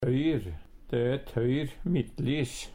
tøyr - Numedalsmål (en-US)